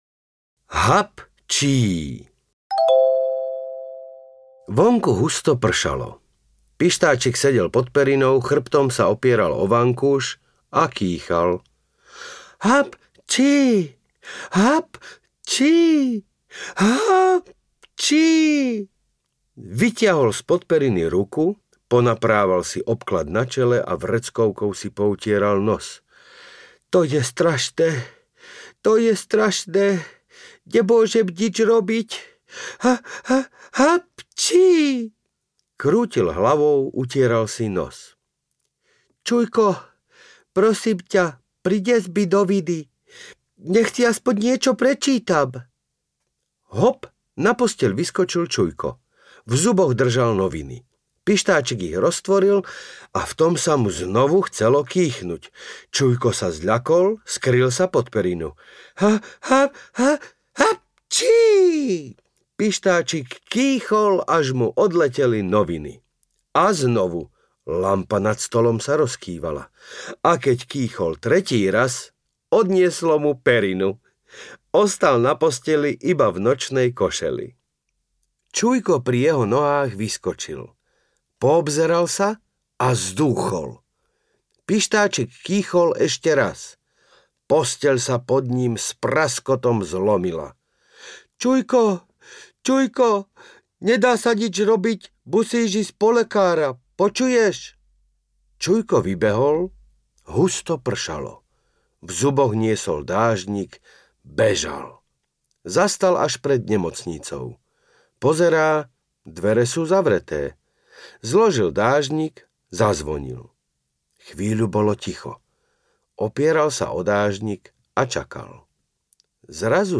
Pištáčik audiokniha
Ukázka z knihy
• InterpretLukáš Latinák